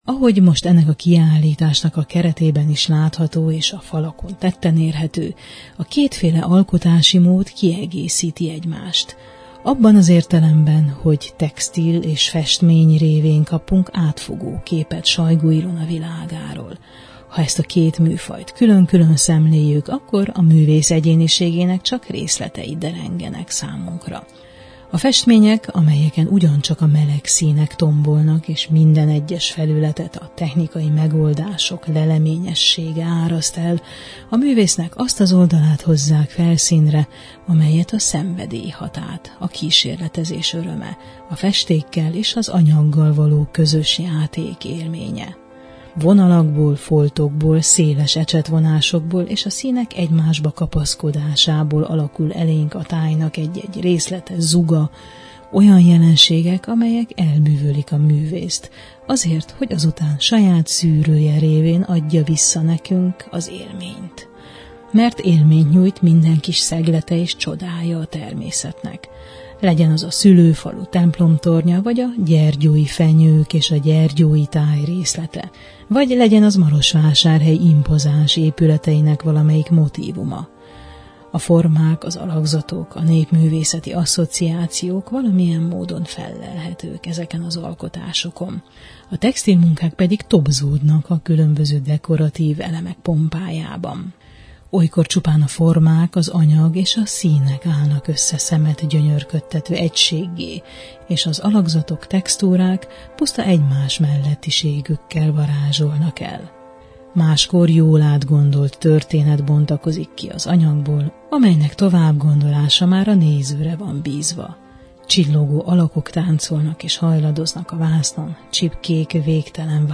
A következőkben erről a két összeforró, ám ugyanakkor mégiscsak különálló világról beszélgetünk